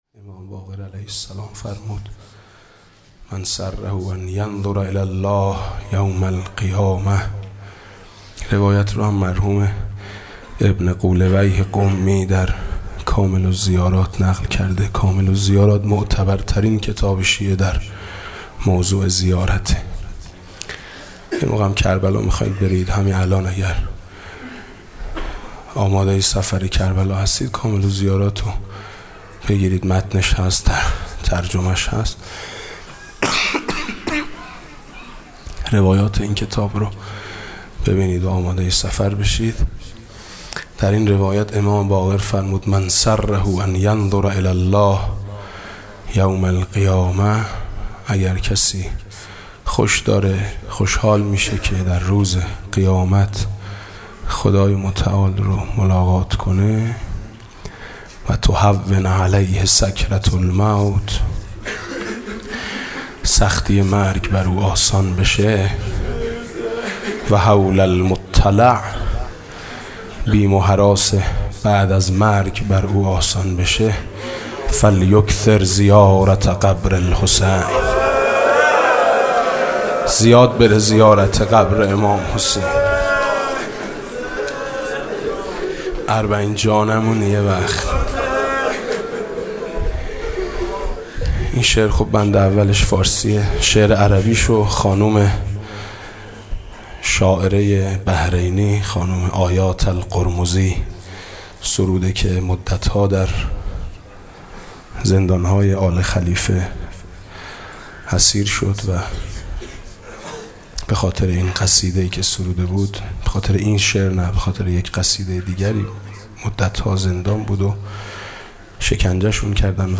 مداحی فارسی عربی